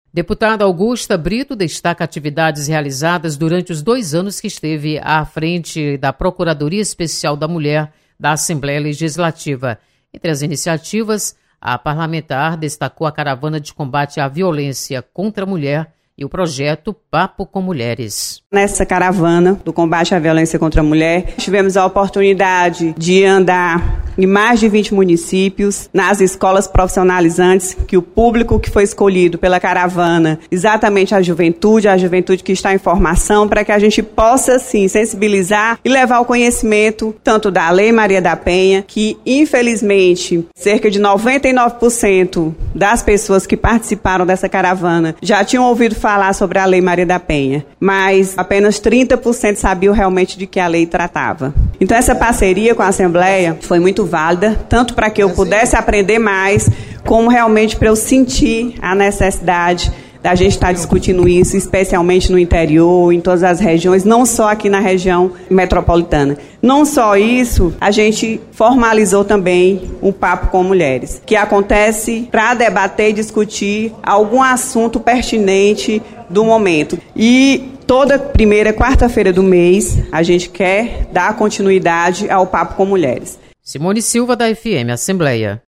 Deputada Augusta Brito faz balanço de sua atuação à frente da procuradoria Especial da Mulher. Repórter